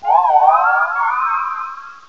pokeemerald / sound / direct_sound_samples / cries / calyrex.aif